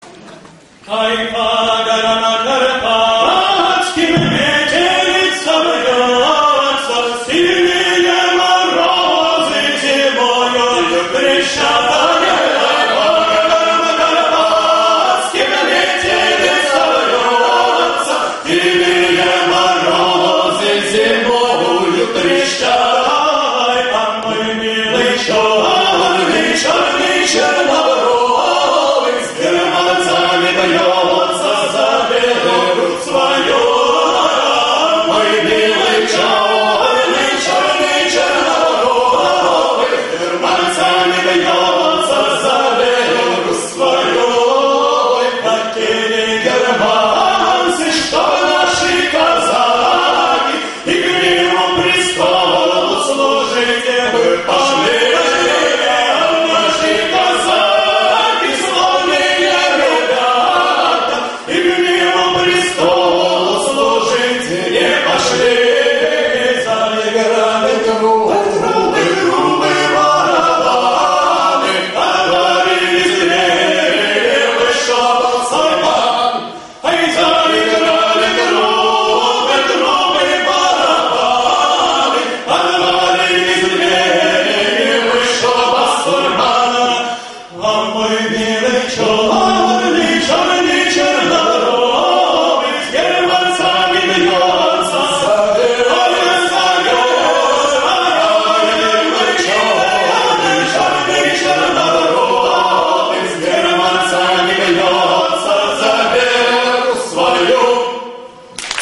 казачьими песнями